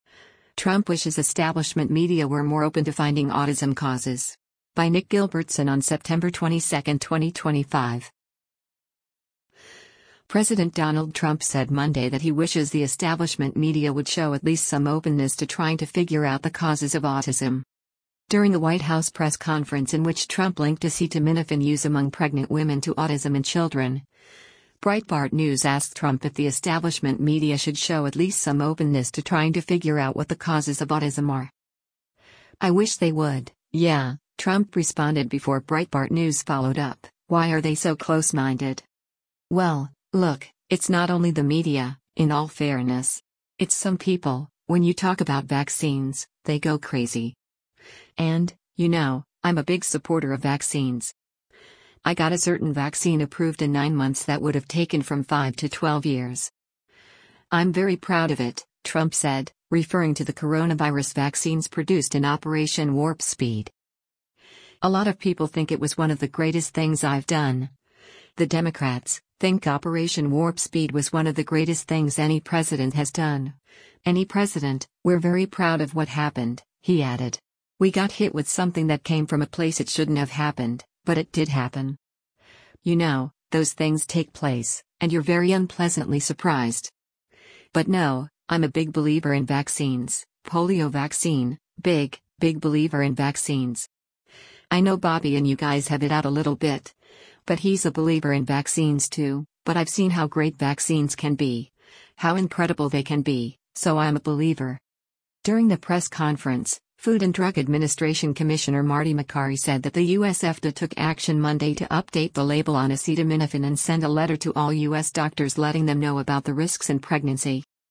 During a White House press conference in which Trump linked acetaminophen use among pregnant women to autism in children, Breitbart News asked Trump if the establishment media should “show at least some openness to trying to figure out what the causes of autism are.”